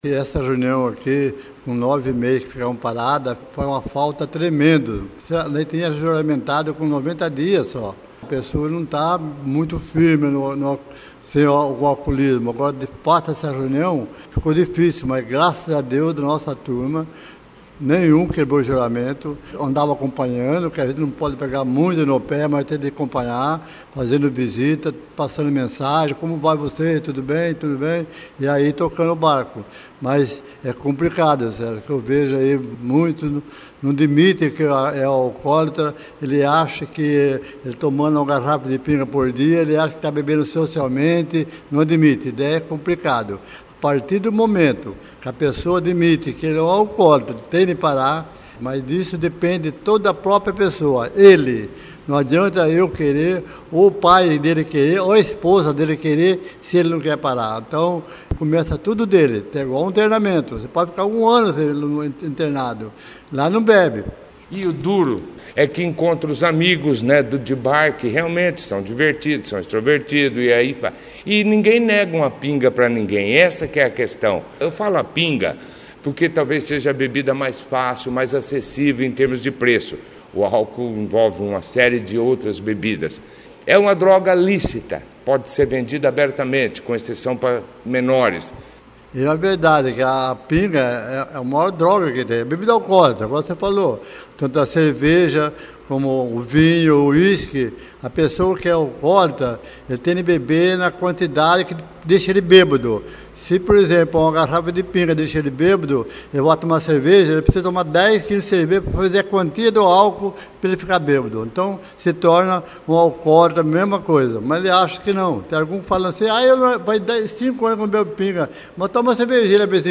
participou da 1ª edição do jornal Operação Cidade desta sexta-feira